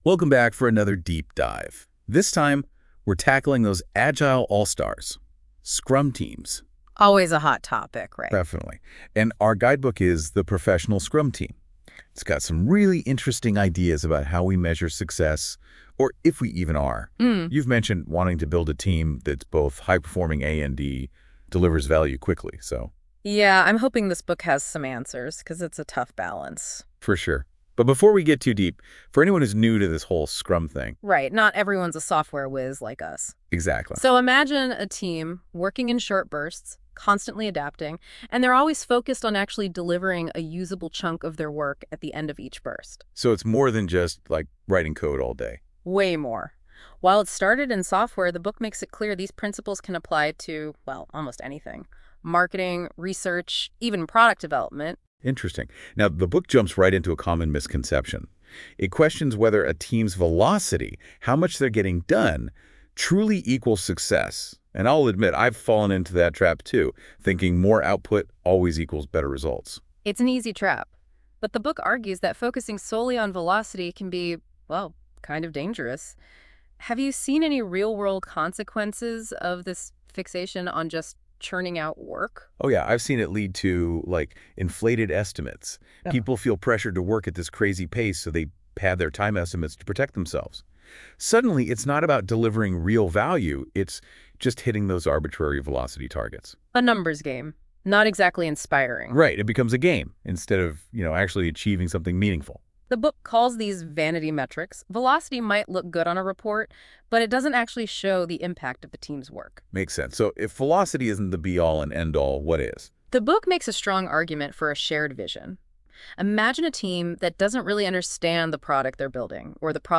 Auto generated Podcast!